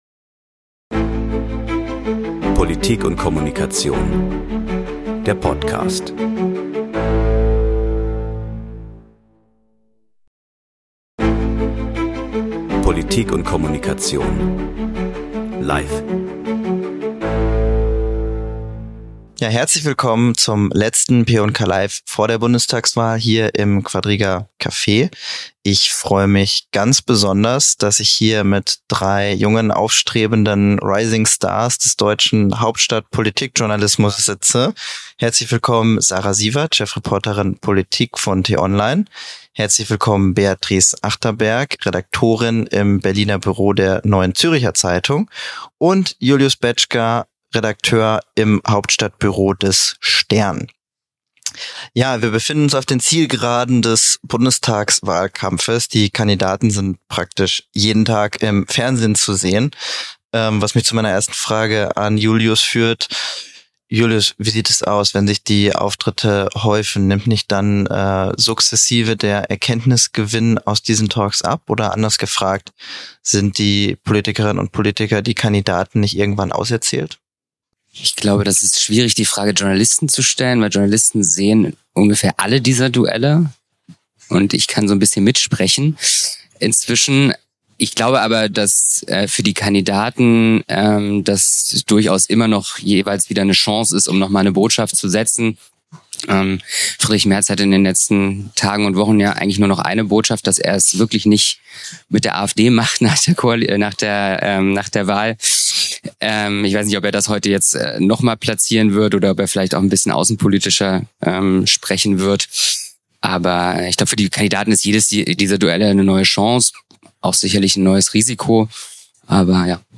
p&k LIVE #3: Jetzt reden die Journalisten ~ Maschinenraum Podcast